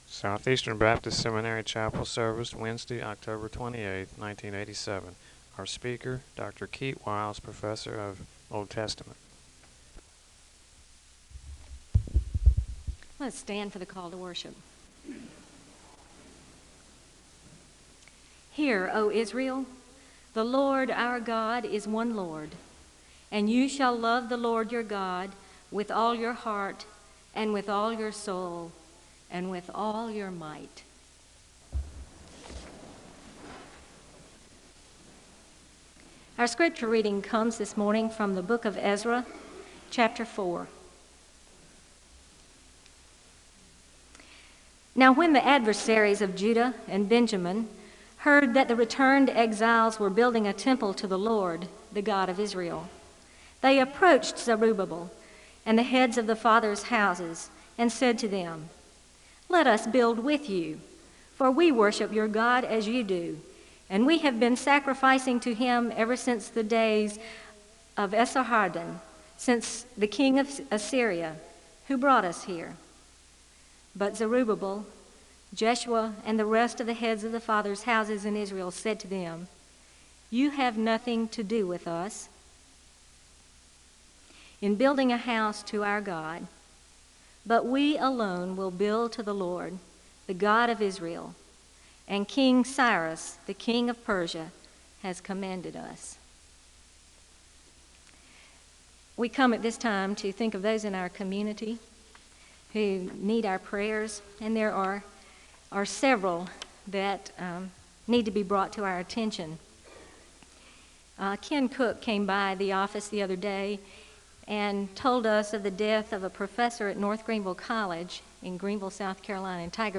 The service begins with a call to worship and a Scripture reading from Ezra (0:00-1:39). Prayer concerns are shared with the congregation and there is a moment of prayer (1:40-5:13).
There is a moment of prayer (6:20-6:44).
The service concludes with a blessing (20:28-20:53).